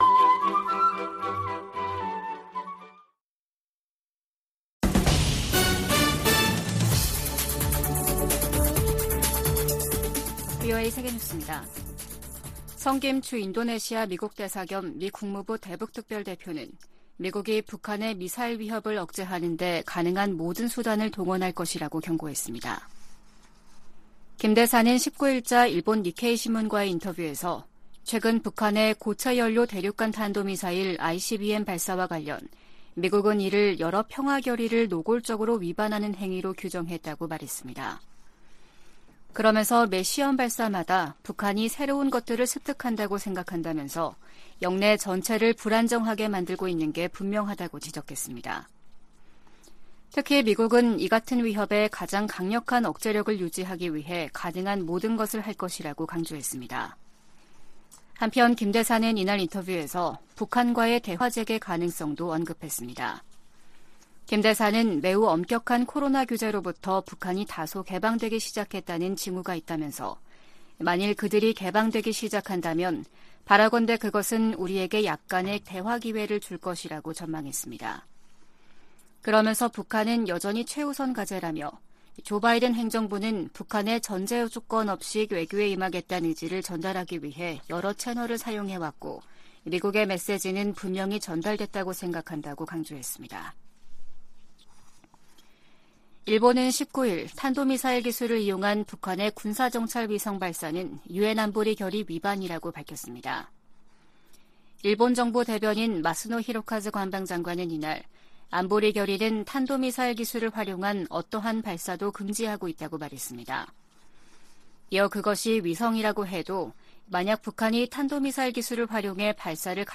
VOA 한국어 아침 뉴스 프로그램 '워싱턴 뉴스 광장' 2023년 4월 20일 방송입니다. 주한미군사령관은 북한 미사일이 워싱턴에 도달할 역량을 갖고 있으며, 7차 핵실험은 시간 문제라고 평가했습니다. 김정은 국무위원장이 첫 군사정찰위성 발사준비를 지시해 머지않아 위성발사 명분 도발에 나설 것으로 보입니다. 토니 블링컨 미 국무장관이 북한의 탄도미사일 발사와 핵 개발을 국제사회 공동 대응 과제로 꼽았습니다.